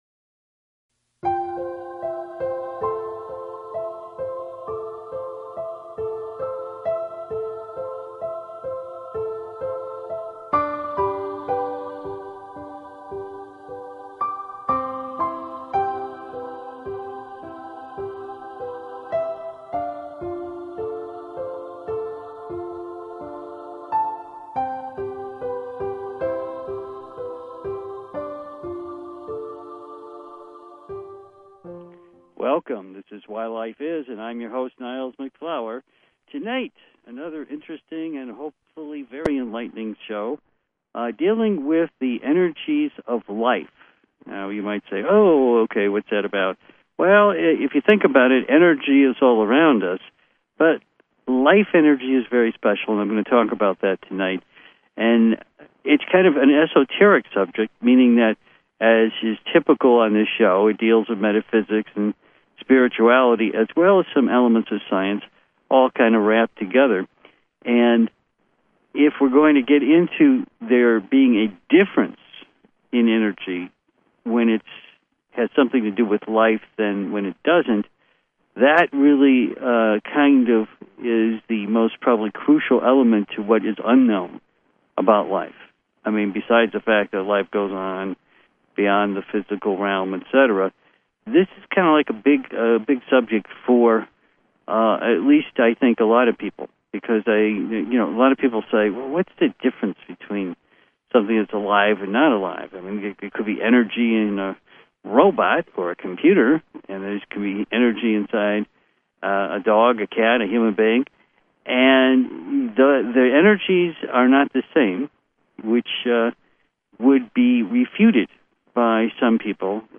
Subscribe Talk Show Why Life Is...